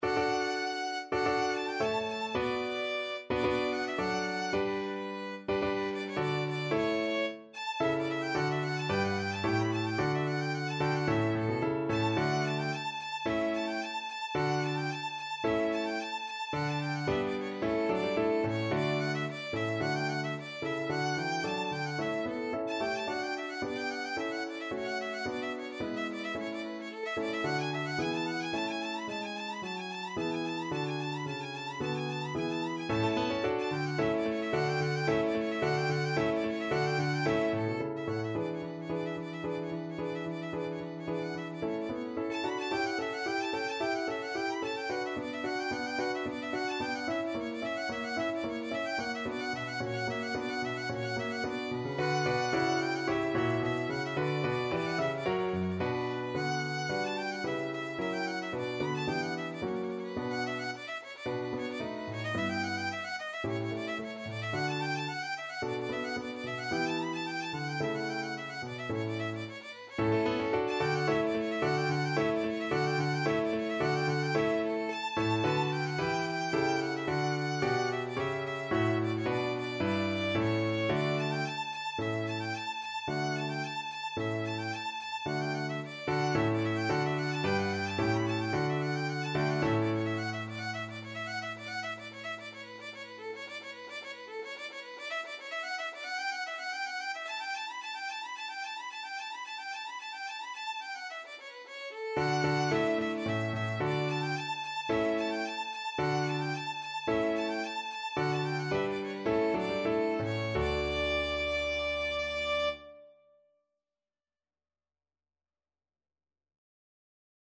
~ = 110 I: Allegro (View more music marked Allegro)
4/4 (View more 4/4 Music)
Classical (View more Classical Violin Music)